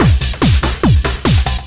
DS 144-BPM A1.wav